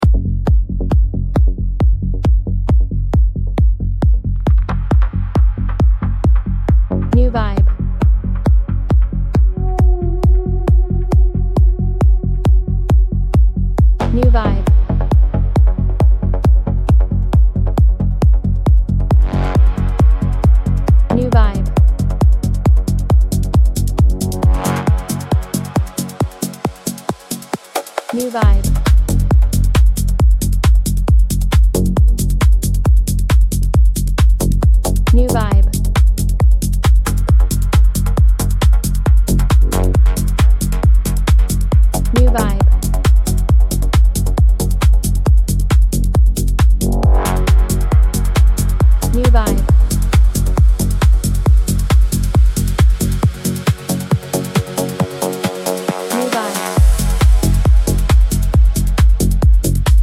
Genre: Beat